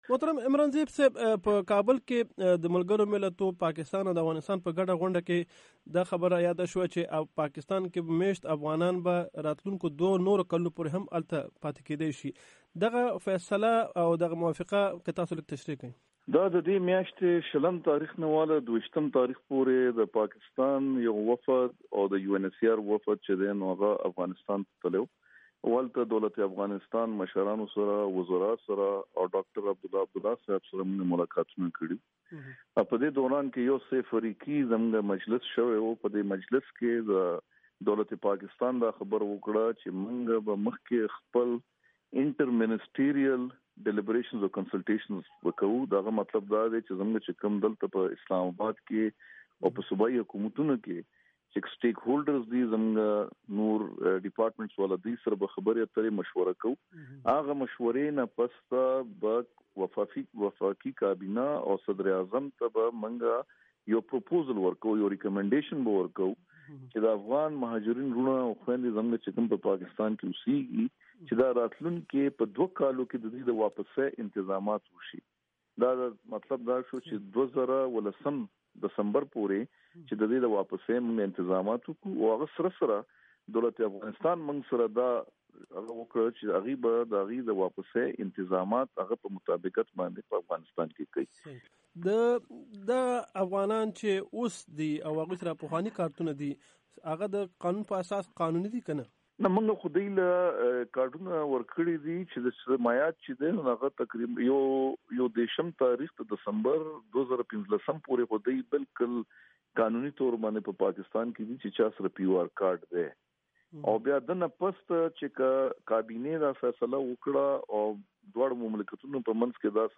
مرکې
ښاغلي عمران زیب د امریکا غږ آشنا راډیو سره په مرکې کې ویلي دي هغه افغان مهاجرین چې اوس په پاکستان کې د اوسیدو قانوني سند لري، هغوي د روان کال ٢٠١٥ د دسمبر تر پایه پاتې کیدی شي او که څوک دغه سند یا پی ار کارډ نه لري نو د هغوي په اړه قانونی گام اخیستل کیږي.